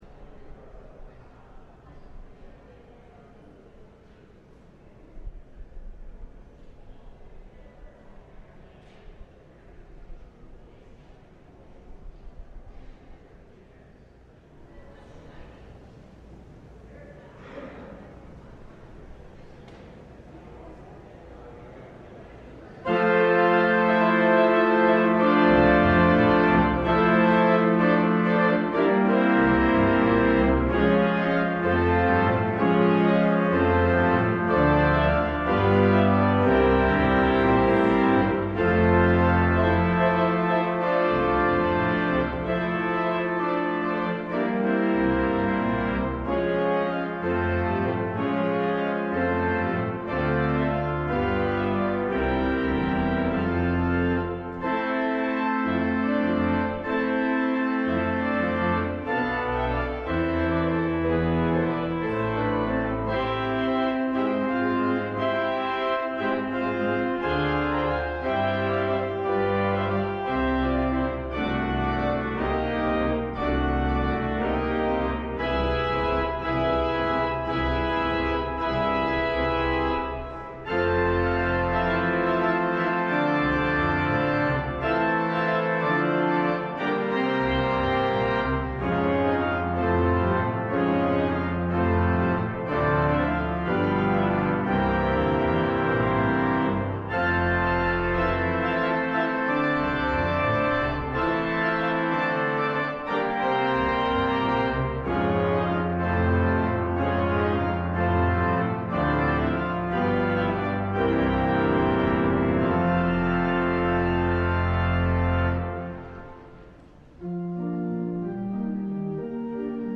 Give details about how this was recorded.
LIVE Morning Service - Cross Words: He Descended into Hell